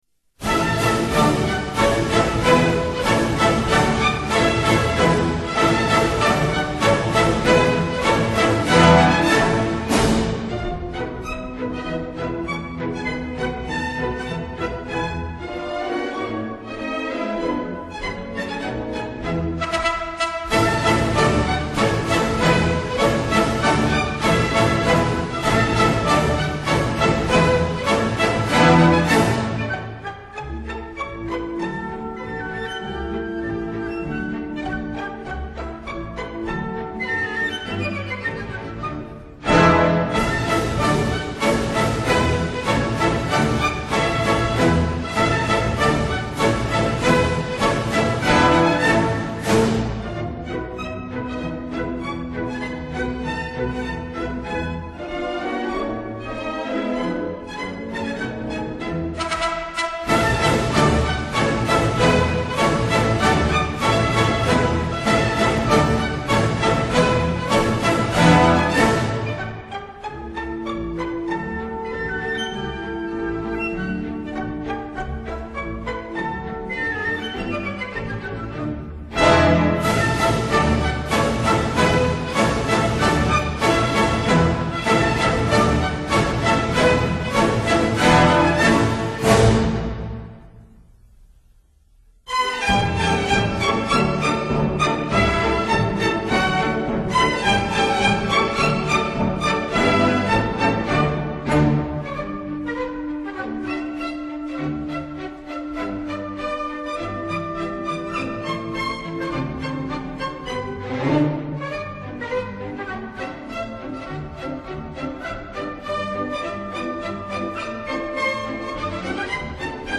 Quadrille